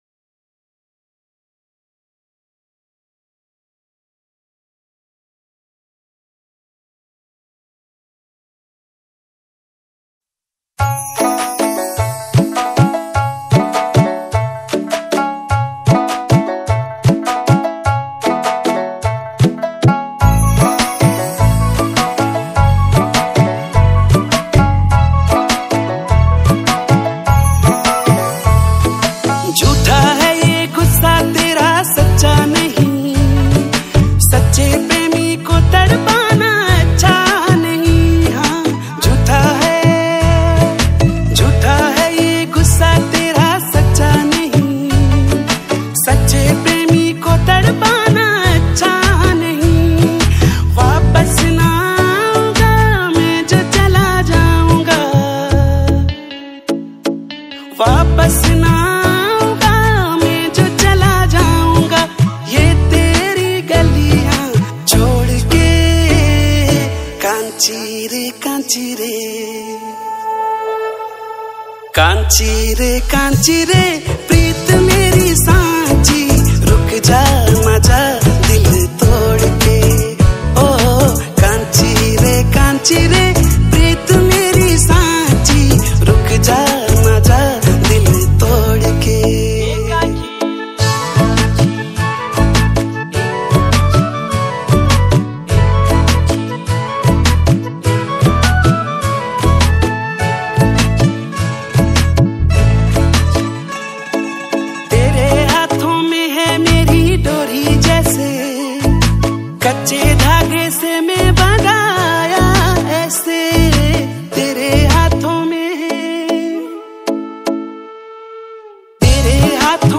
himachali pahari songs
Himachali Songs